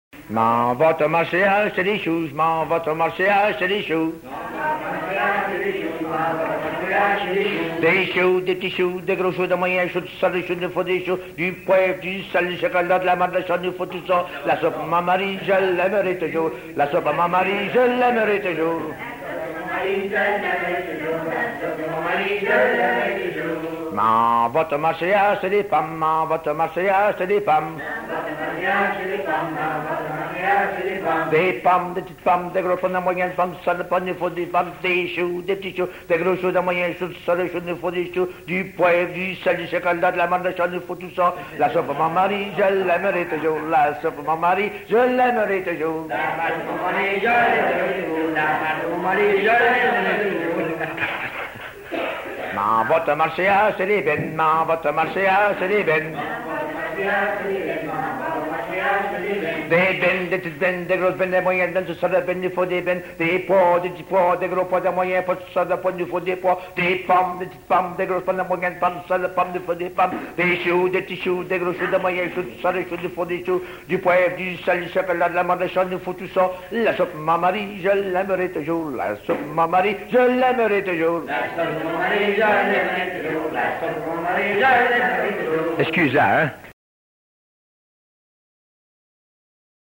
Folk Songs, French--New England
Song